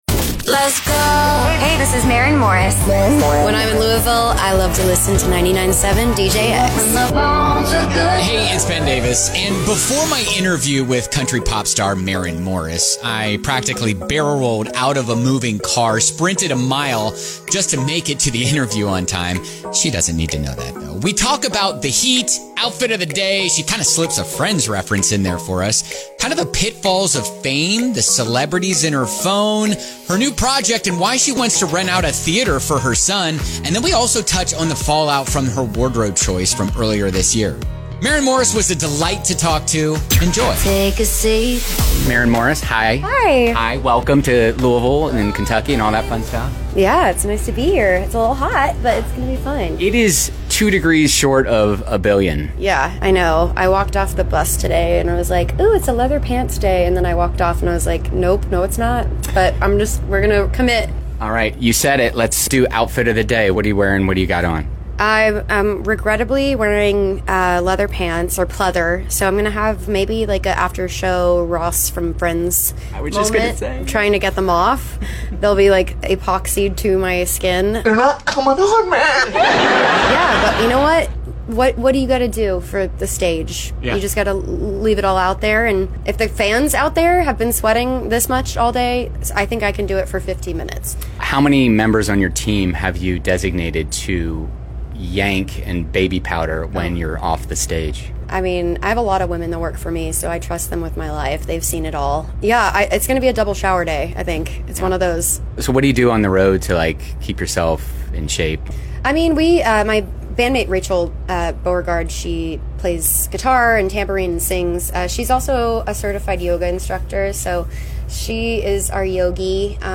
Maren Morris Bourbon & Beyond Interview